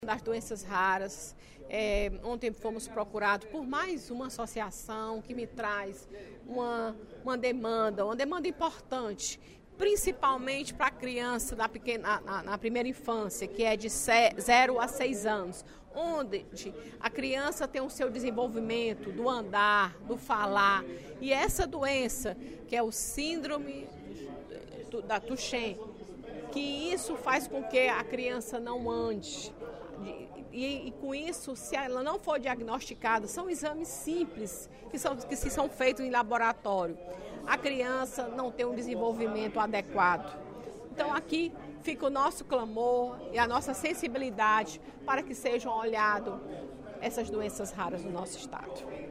A deputada Fernanda Pessoa (PR) manifestou, durante o primeiro expediente da sessão plenária desta quarta-feira (05/07), apoio à luta por melhores condições de saúde e sobrevivência aos portadores da Distrofia de Duchenne, um tipo de doença genética degenerativa e incapacitante.